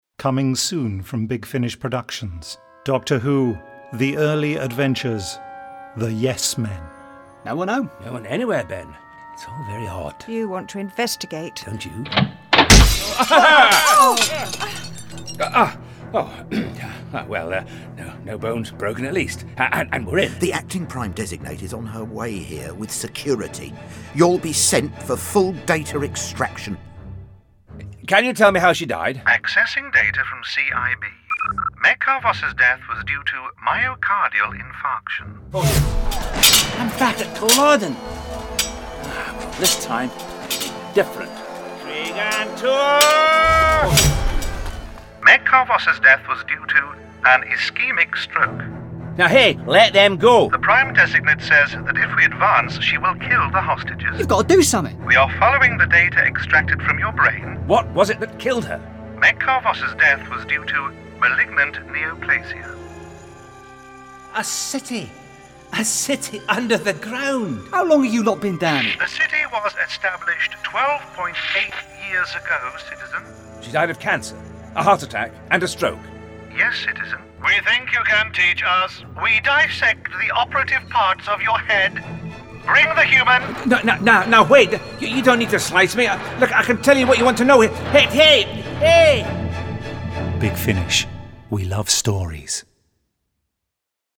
full-cast original audio dramas
Starring Anneke Wills Frazer Hines
trailer